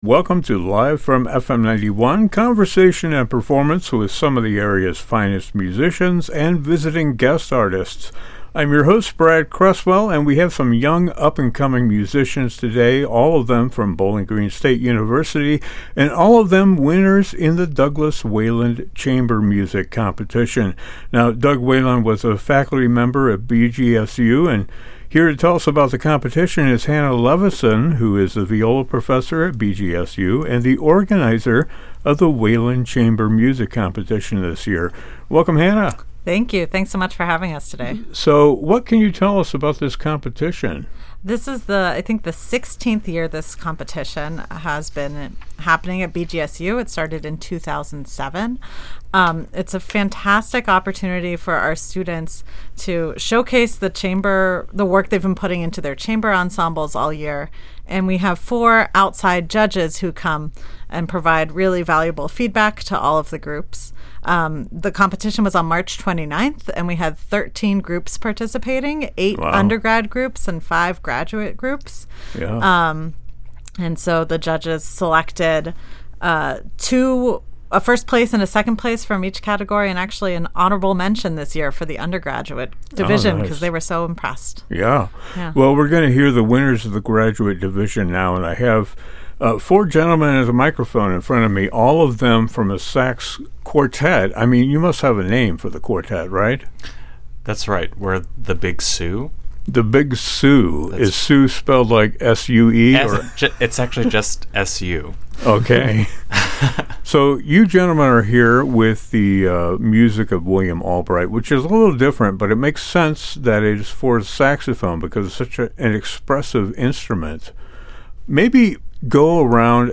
Winners of the Douglas Wayland Chamber Competition at BGSU appear in conversation and performance.
The winners of the graduate division,The Big Su (saxophone quartet), perform music by William Albright, and the second-place winners of the undergrad division, The Vyšehrad Trio, perform music by Antonín Dvořák.